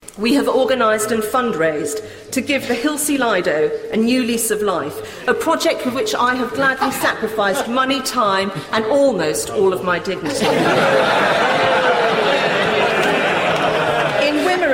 Penny Mordaunt's Loyal Address, House of Commons, 4 June 2014